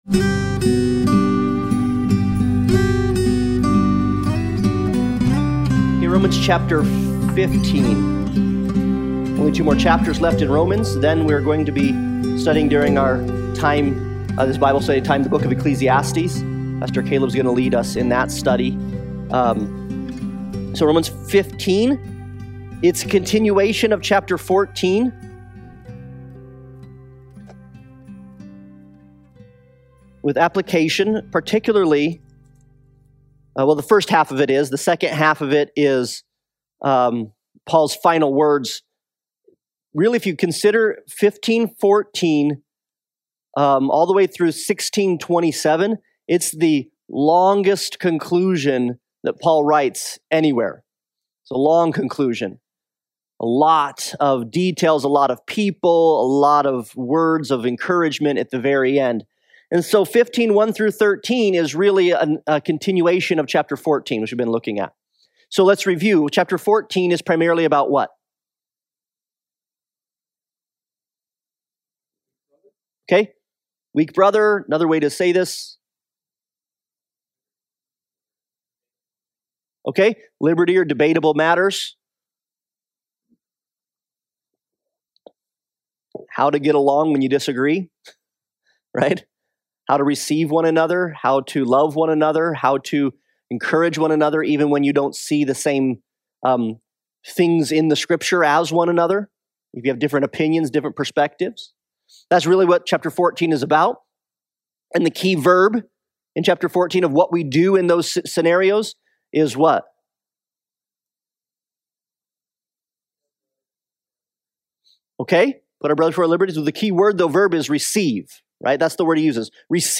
Passage: Romans 15:1-11 Service Type: Sunday Bible Study